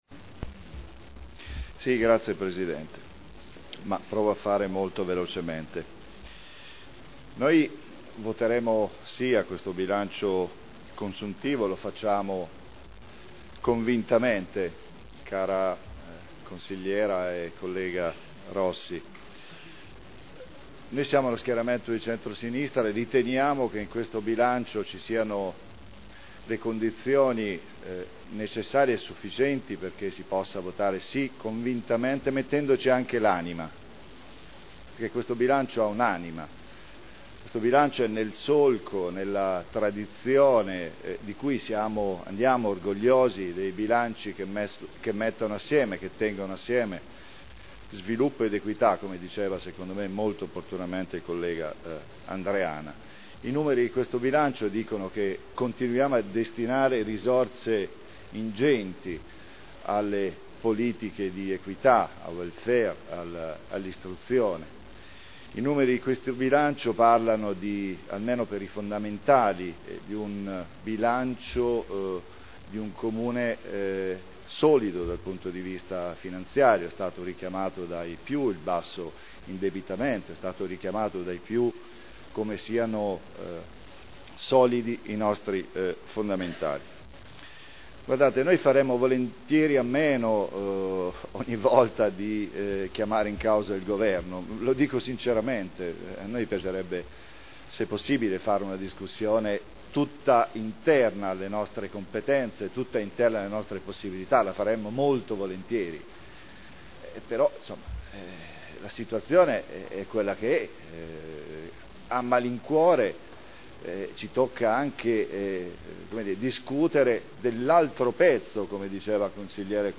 Paolo Trande — Sito Audio Consiglio Comunale
Rendiconto della Gestione del Comune di Modena per l’esercizio 2009 - Approvazione Dichiarazioni di voto